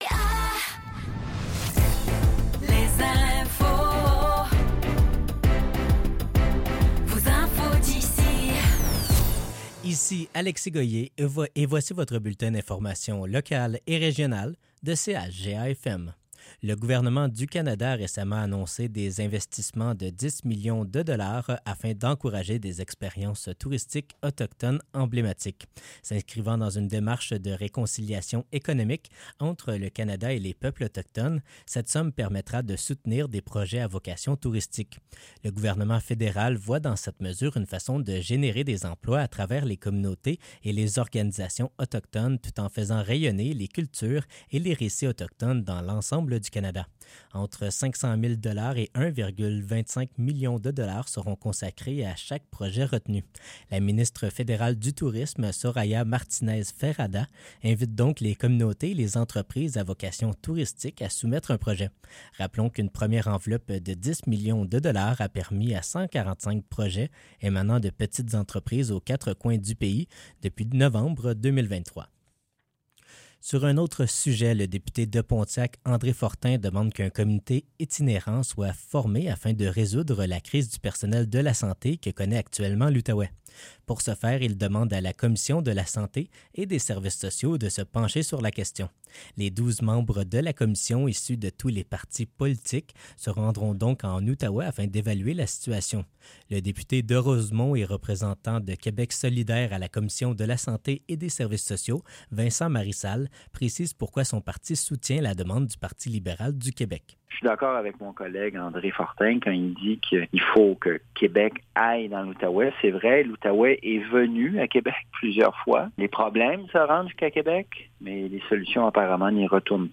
Nouvelles locales - 14 mai 2024 - 15 h